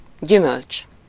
Ääntäminen
Ääntäminen Tuntematon aksentti: IPA: /ˈfrɵkt/ Haettu sana löytyi näillä lähdekielillä: ruotsi Käännös Ääninäyte 1. gyümölcs Artikkeli: en .